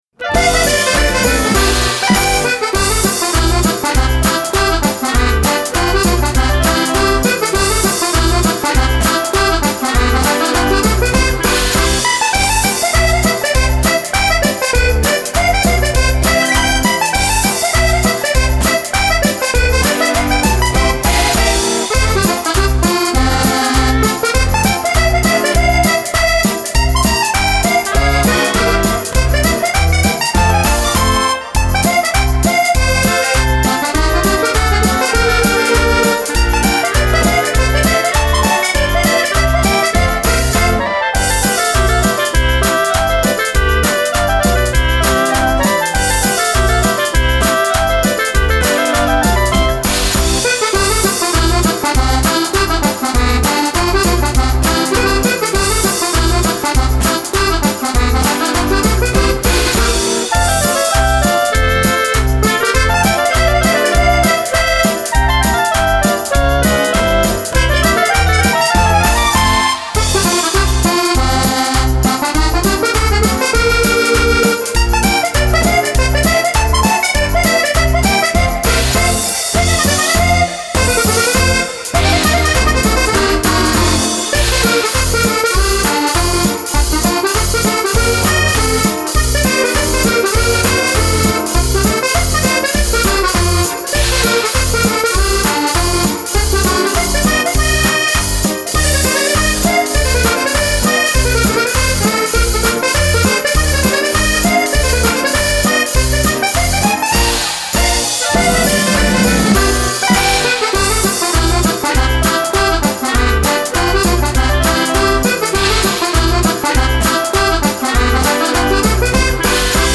la version accordéon  intégrale